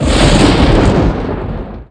SFX魔法雷电技能音效下载
SFX音效